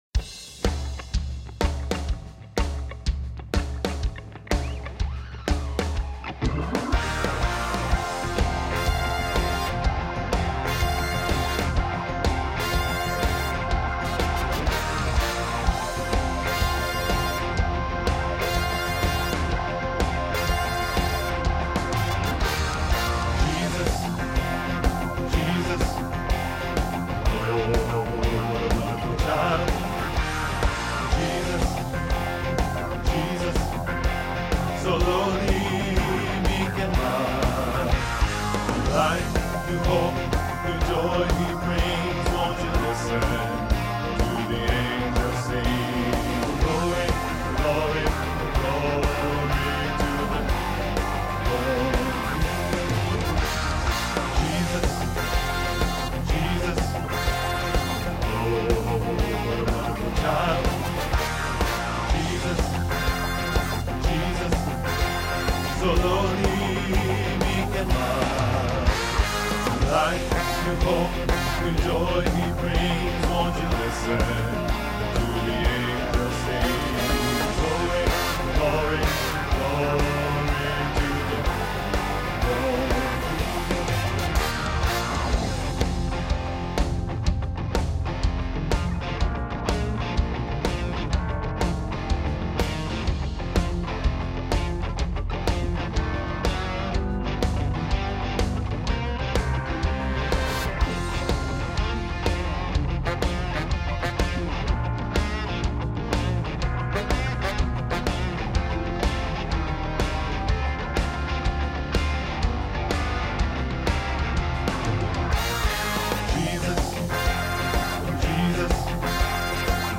There are full tracks to listen to, and individual tracks for each voice part.
Jesus, What a wonderful child-Bass
08-Jesus-What-a-wonderful-child-Bass.wma